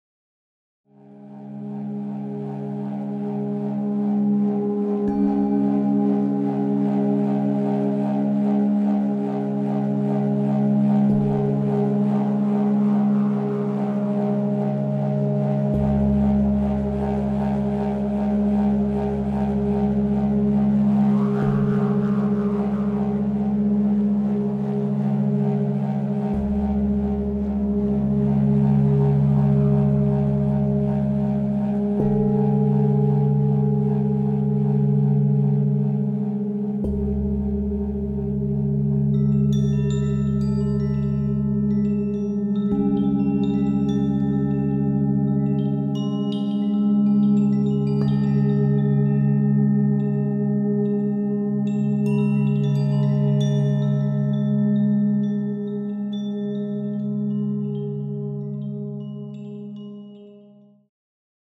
Diese CD besitzt sehr tiefe und sehr hohe Töne.